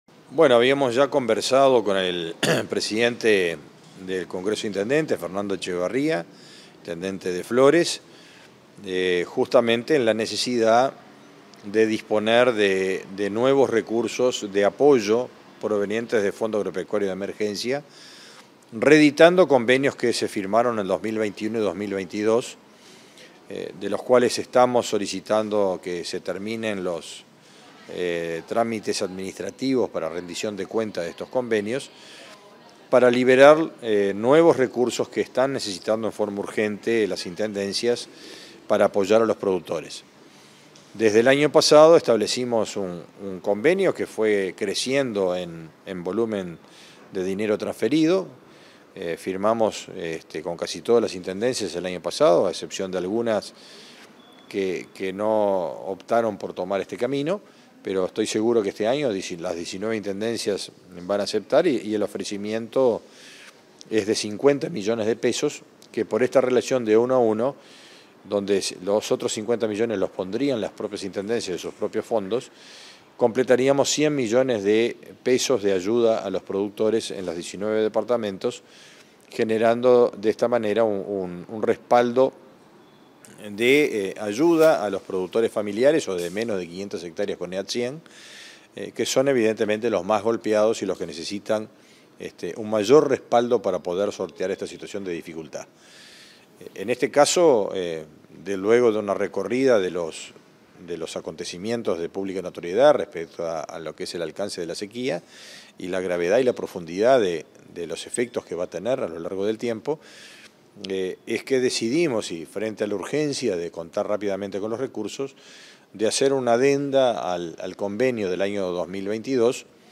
Entrevista al ministro de Ganadería, Agricultura y Pesca, Fernando Mattos